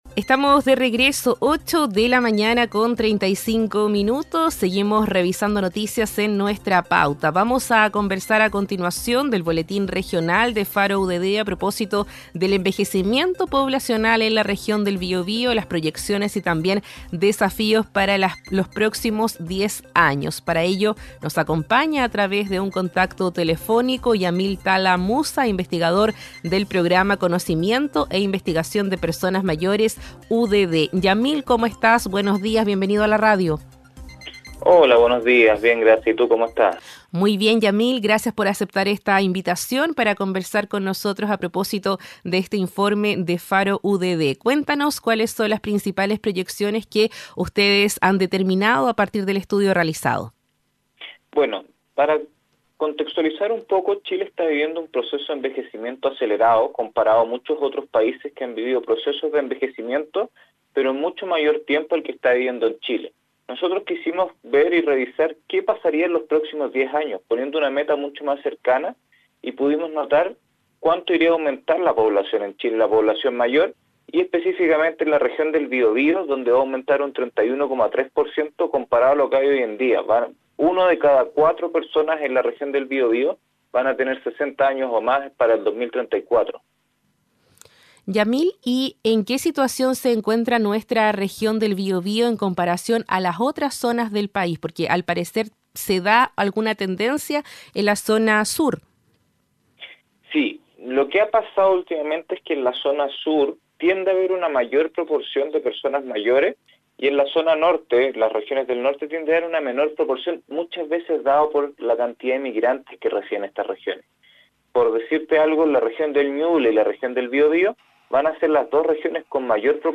11-Entrevista-Faro-UDD.mp3